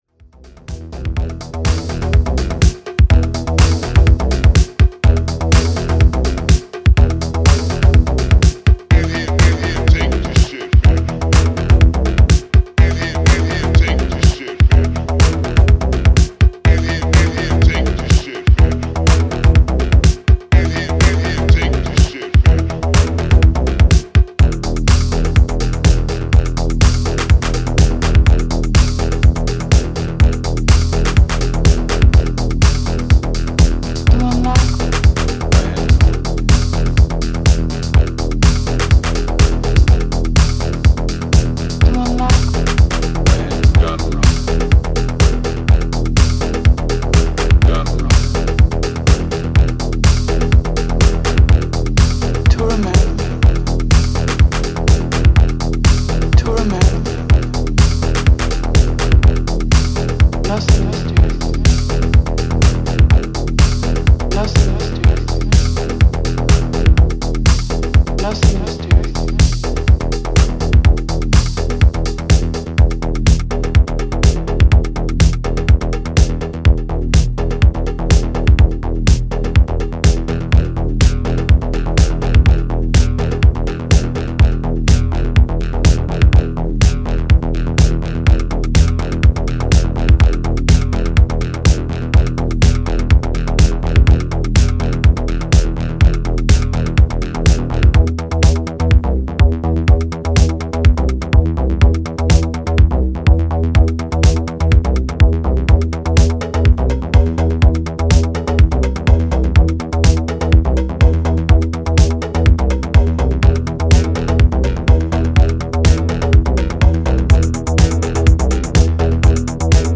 初期シカゴ・ハウスとEBMが交差する